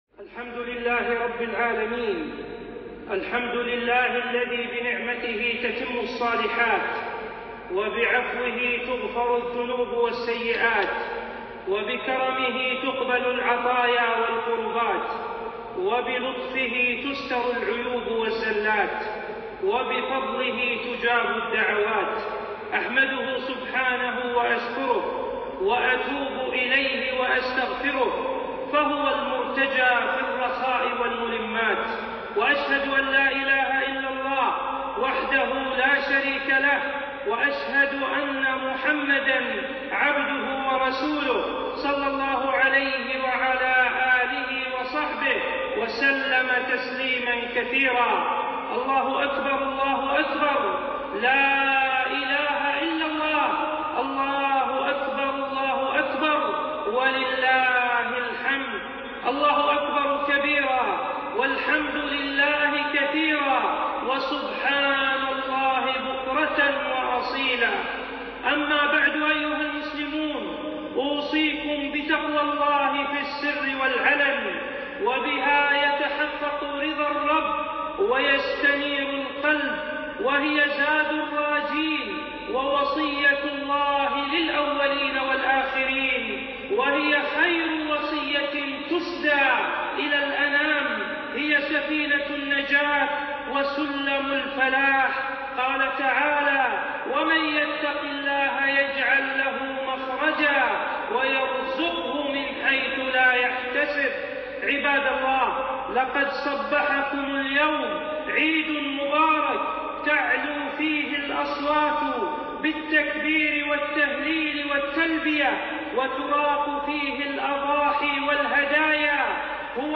خطبة عيد الأضحى عام 1441هـ